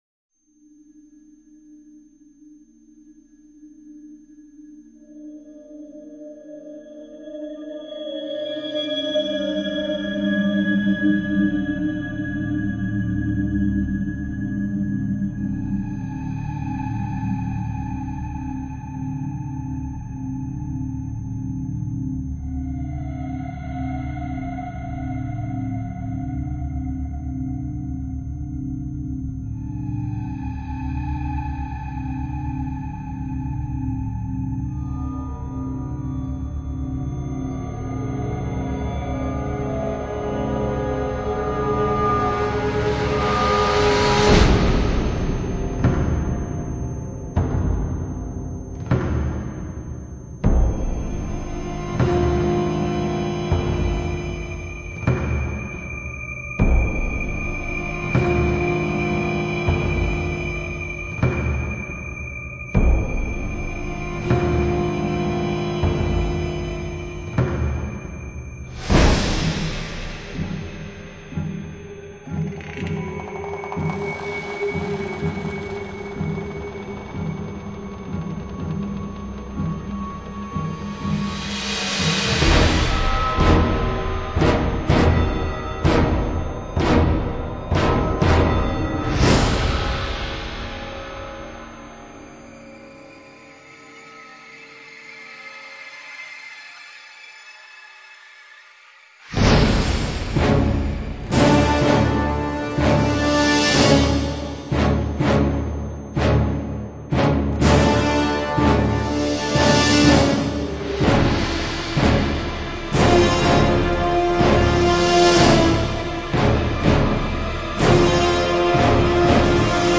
Promotional CD tracks